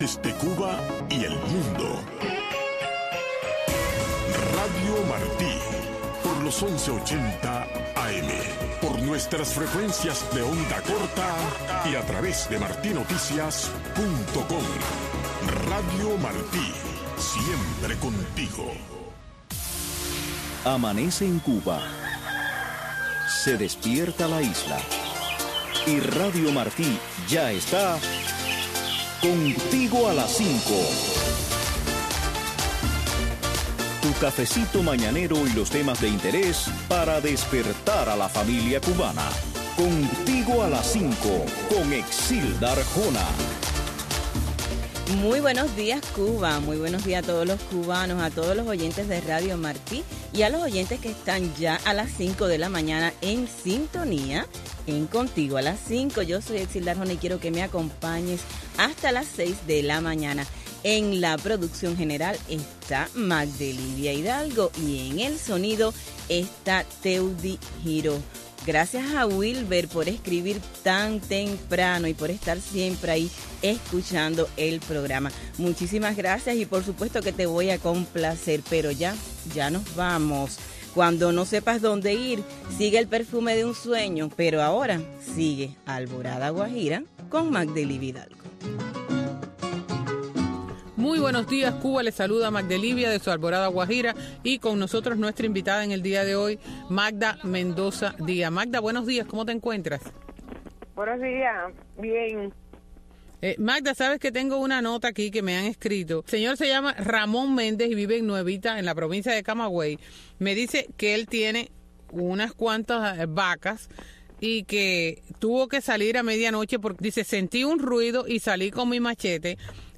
Revista informativa, cultural, noticiosa